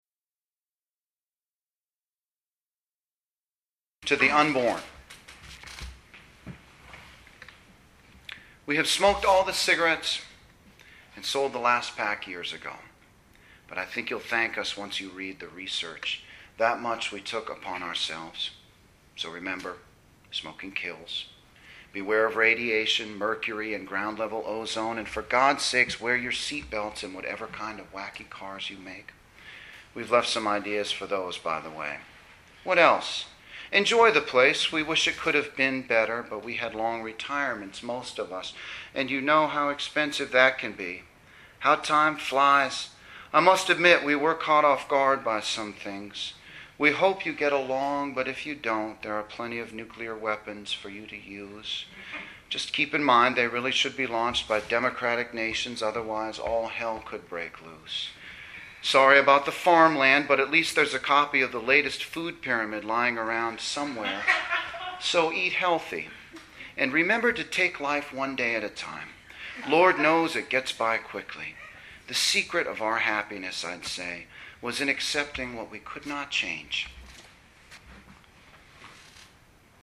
To the Unborn (live, Bowdoin)
To_the_Unborn_live.mp3